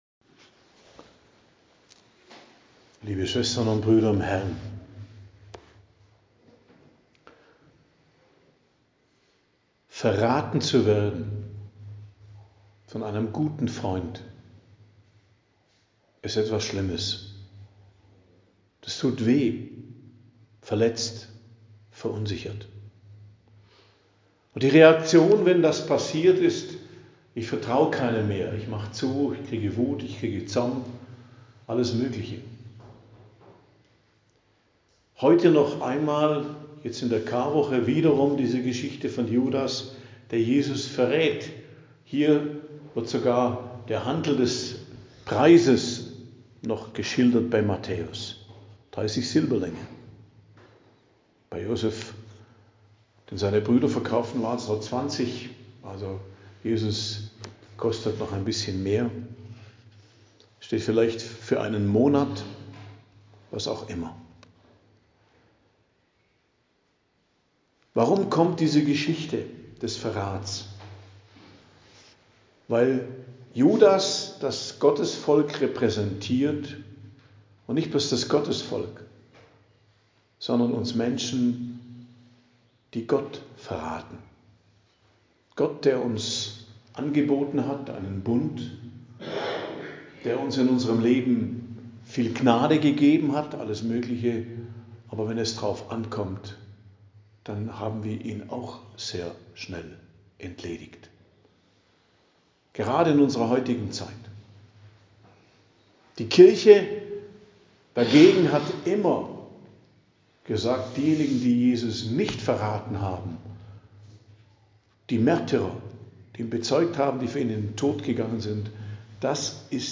Predigt am Mittwoch der Karwoche, 16.04.2025 ~ Geistliches Zentrum Kloster Heiligkreuztal Podcast